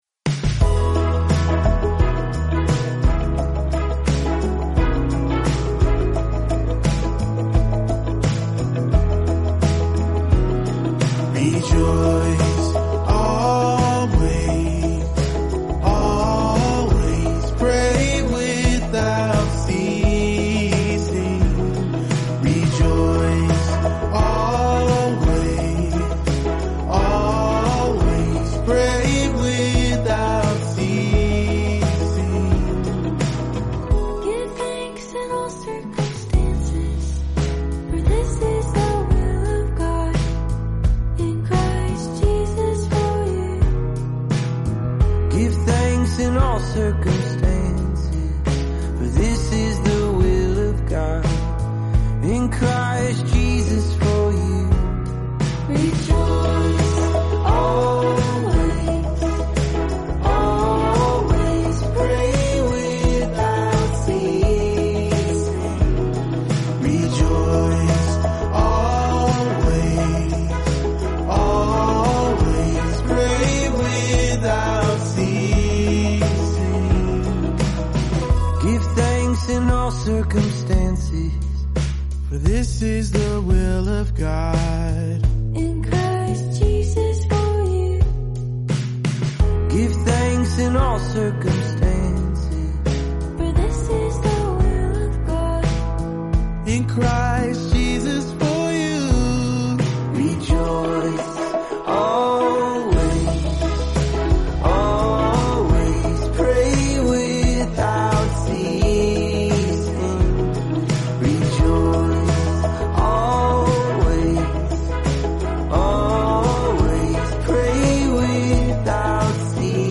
word-for-word Scripture song